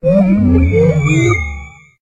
Cri de Mushana dans Pokémon HOME.